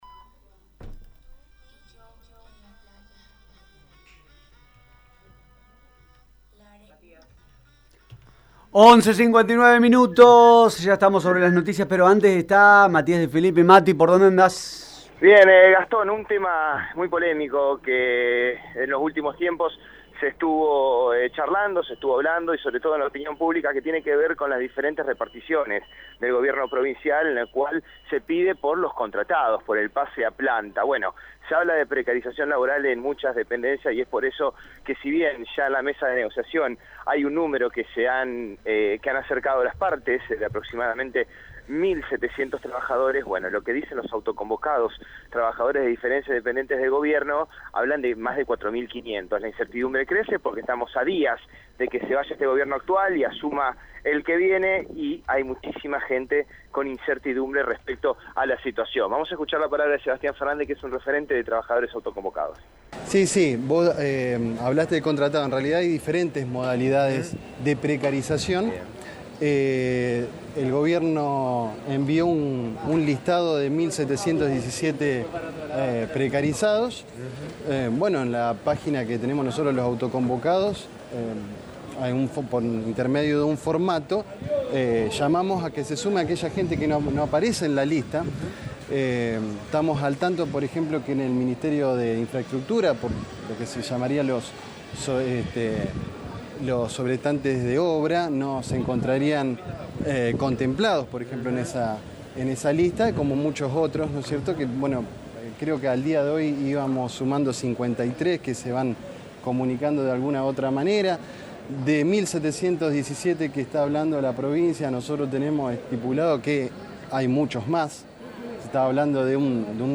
explicó en el móvil de Radio EME que «el gobierno envió un listado de 1700 trabajadores pero en la página que tenemos llamamos a que se registre el personal que no está en la lista«.